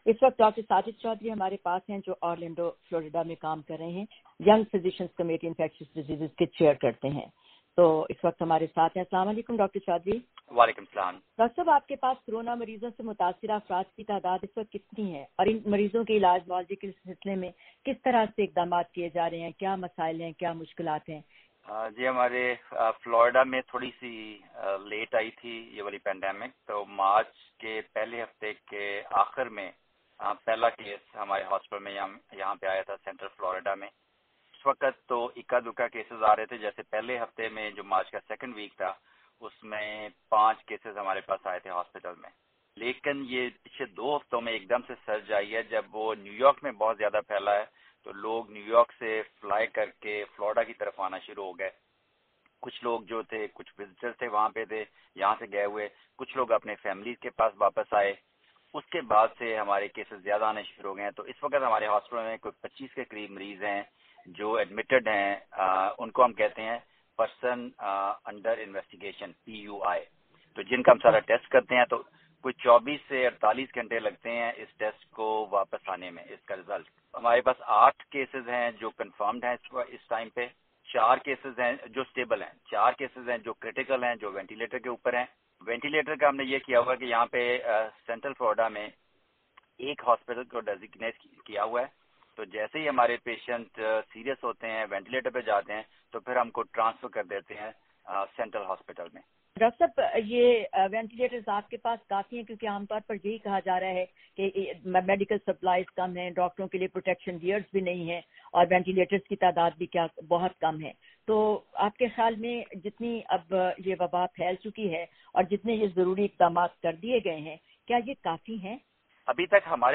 by وائس آف امریکہ